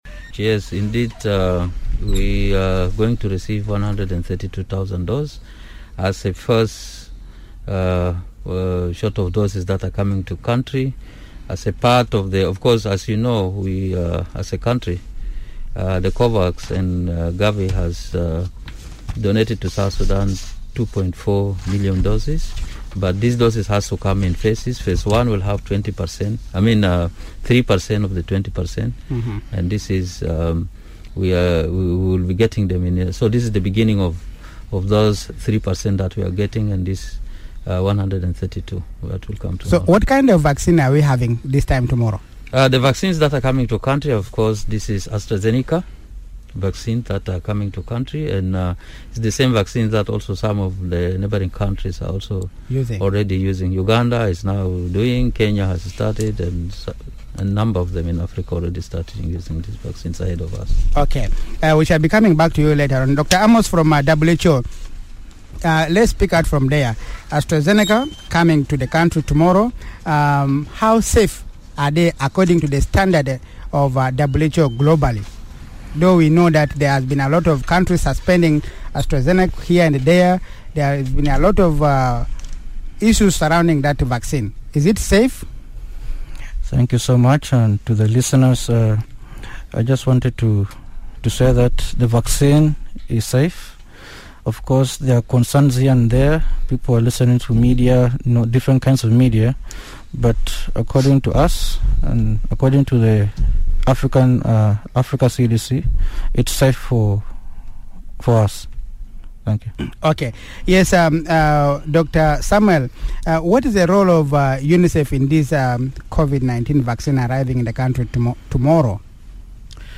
DISCUSSION: South Sudan COVID-19 vaccination program
This panel of experts delves into the specifics of the vaccines; numbers, make, roll-out plan and safety.